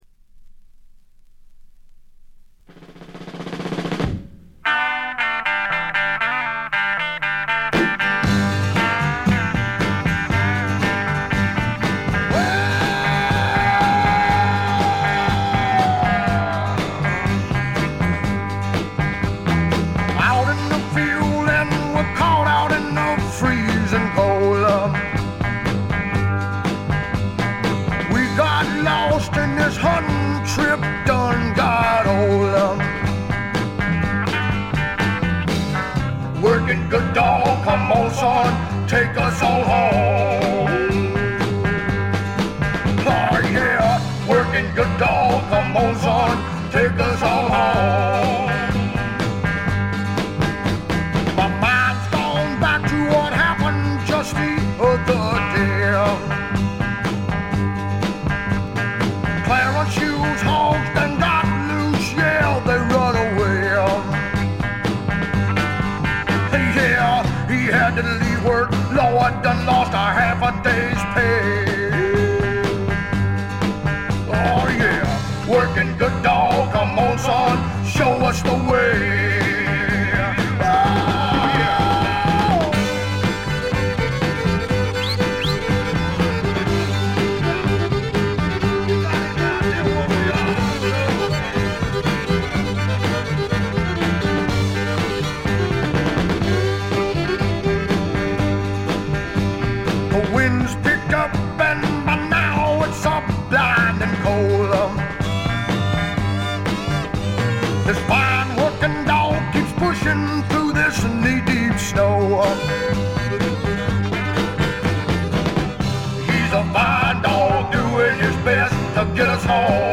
部分試聴ですが、ほとんどノイズ感無し。
肝心の音はといえば南部の湿った熱風が吹きすさぶ強烈なもの。
試聴曲は現品からの取り込み音源です。
Fiddle